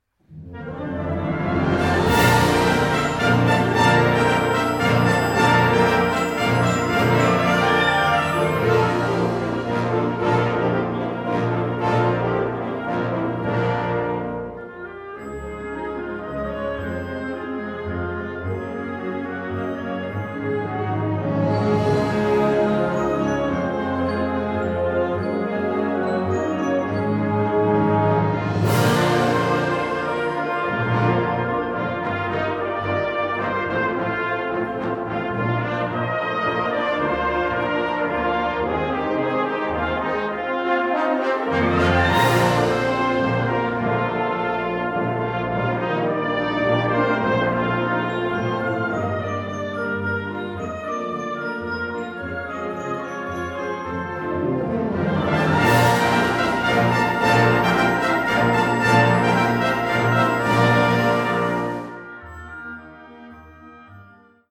Categorie Harmonie/Fanfare/Brass-orkest
Subcategorie Concertmuziek
Bezetting Ha (harmonieorkest)